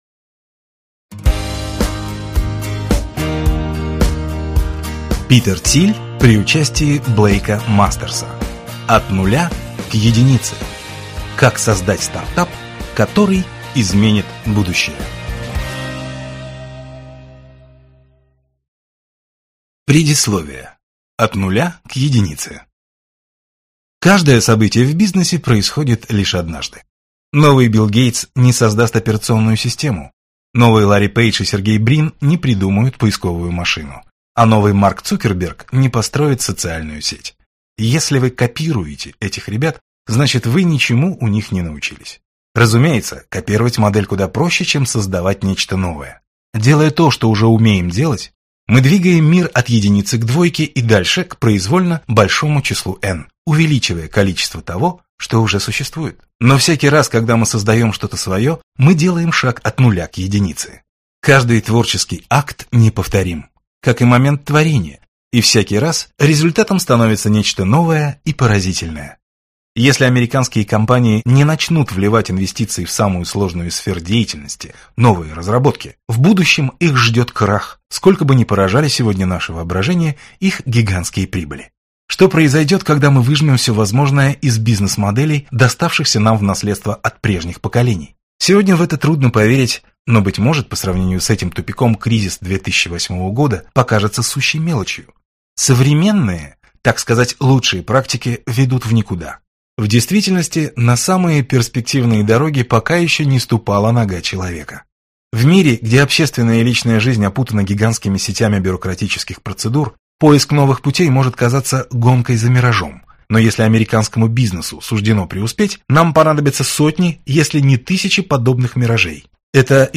Аудиокнига От нуля к единице. Как создать стартап, который изменит будущее | Библиотека аудиокниг